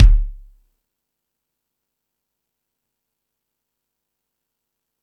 Lotsa Kicks(40).wav